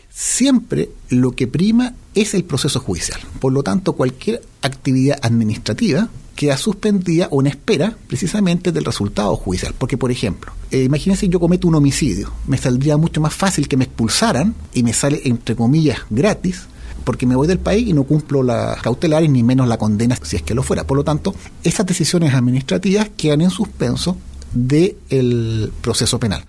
En conversación con La Radio en Puerto Montt, el vocero de dicha corte, Patricio Rondini, aclaró algunas dudas respecto a la notificación de expulsión de 13 colombianos -la mayoría irregulares en el país- que agredieron a efectivos policiales durante un procedimiento surgido tras la denuncia por un robo en el sector Mirasol.